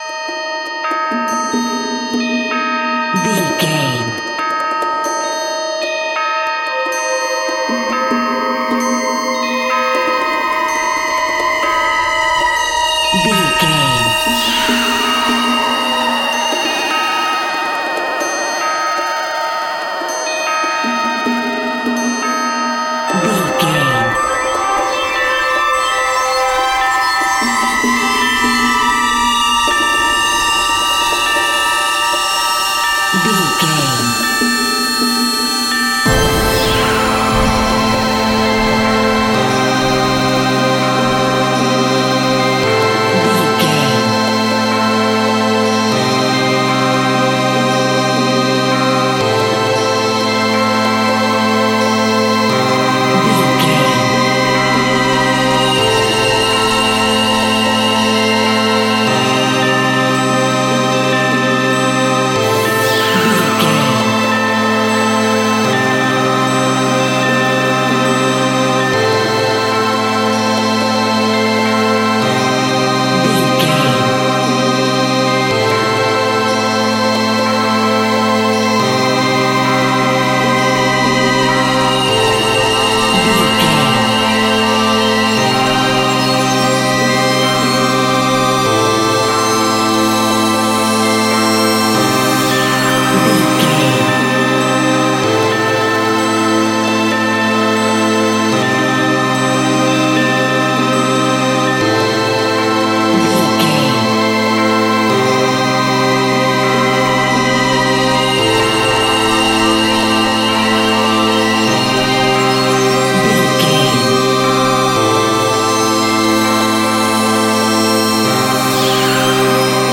In-crescendo
Thriller
Aeolian/Minor
scary
ominous
dark
haunting
eerie
synthesiser
percussion
strings
horror
Horror Synths
Scary Strings